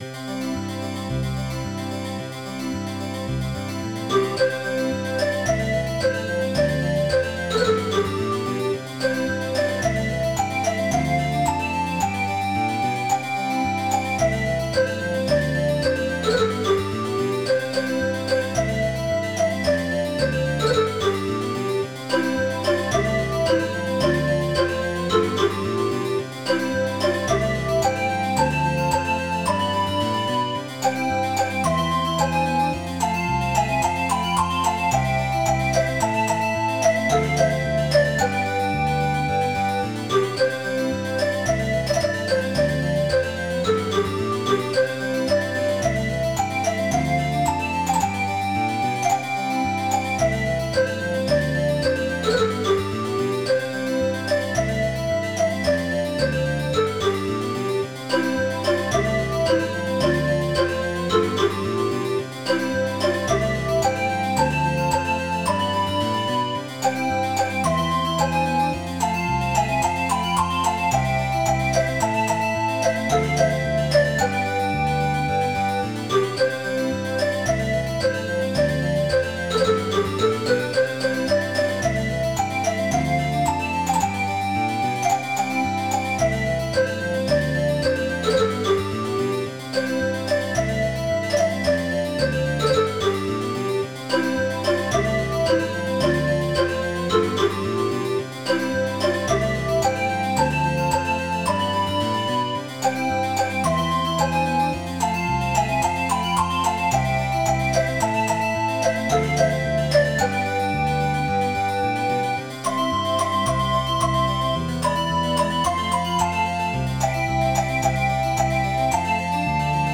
nancyw.mid.ogg